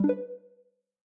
Added menu sounds
notice.ogg